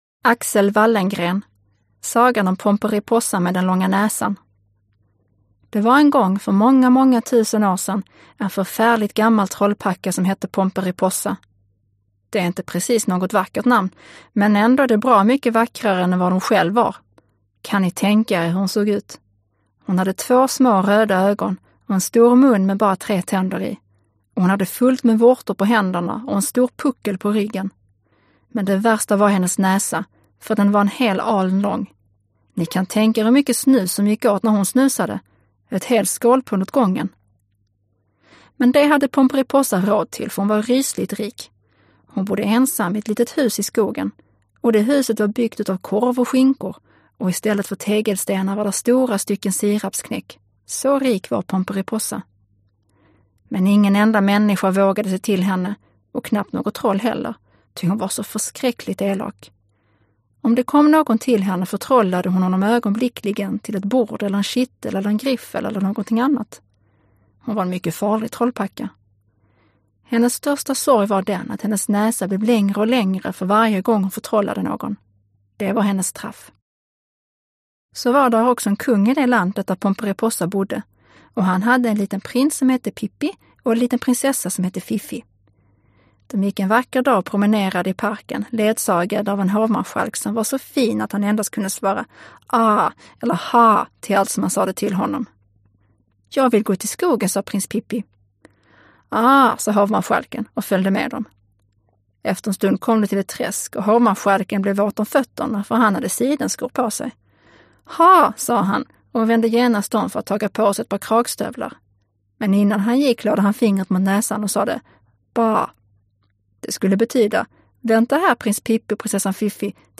Аудиокнига Шведские литературные сказки | Библиотека аудиокниг
Прослушать и бесплатно скачать фрагмент аудиокниги